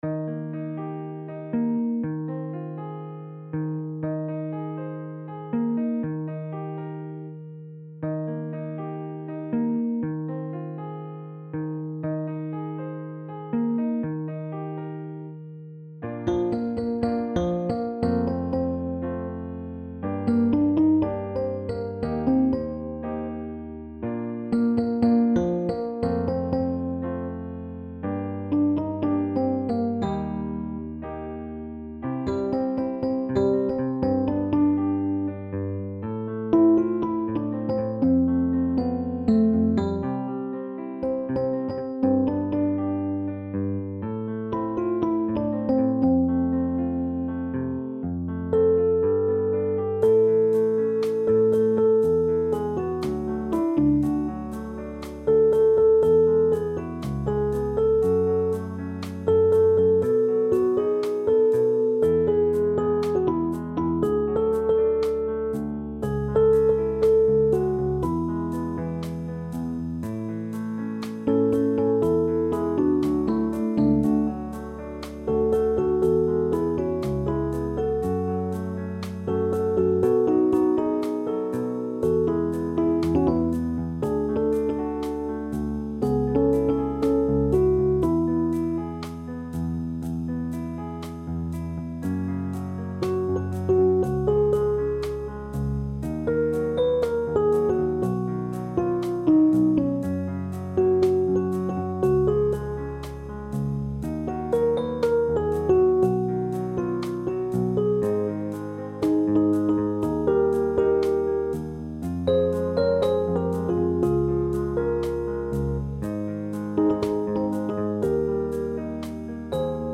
met fraaie modulaties